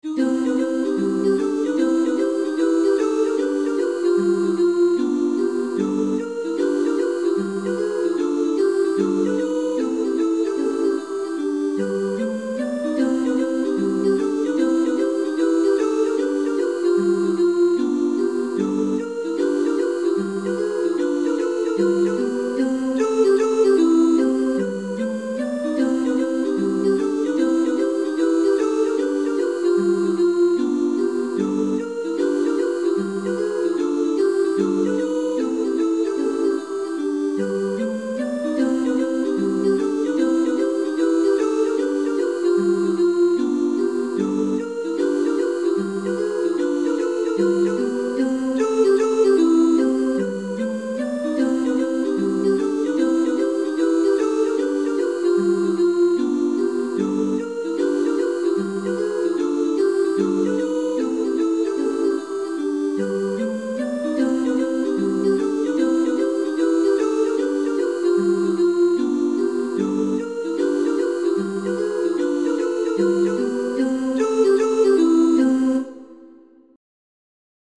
Enregistrement chanté